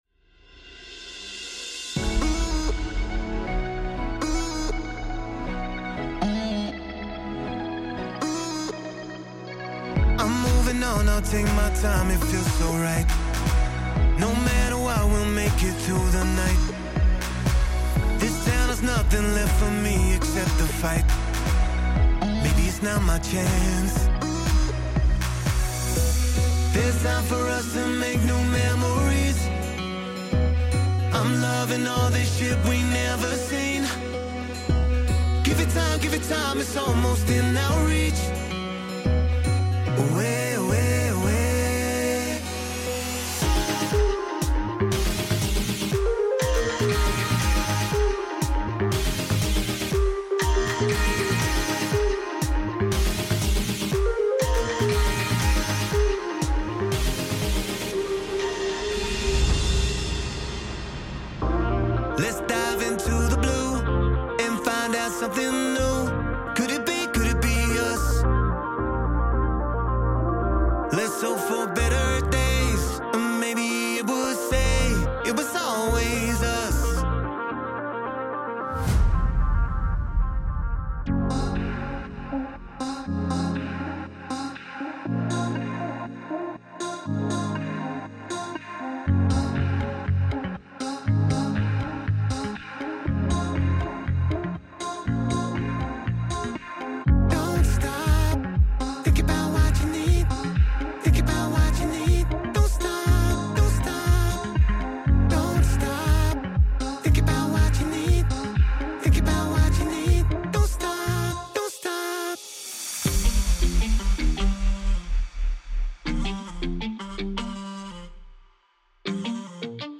•A variety of BPMs (110, 120 & 160 BPM)
Demo